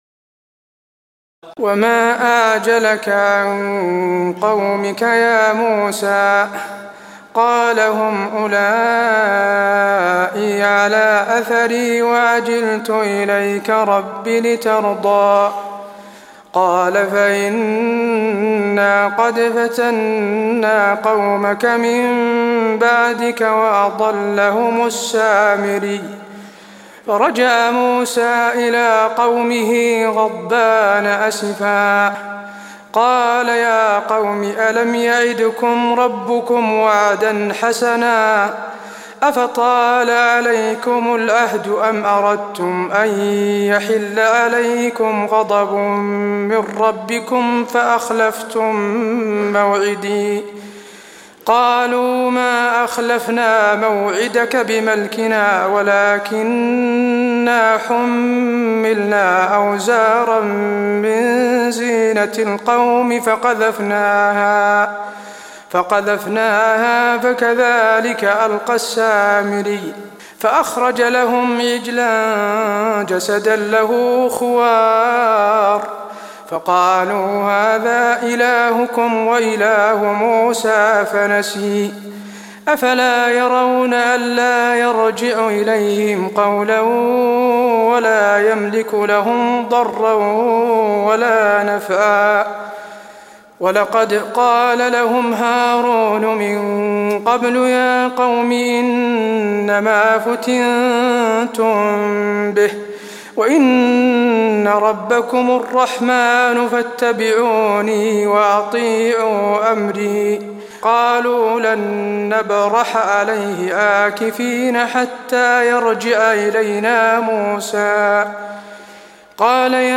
تراويح الليلة الخامسة عشر رمضان 1423هـ من سورة طه (83-135) Taraweeh 15 st night Ramadan 1423H from Surah Taa-Haa > تراويح الحرم النبوي عام 1423 🕌 > التراويح - تلاوات الحرمين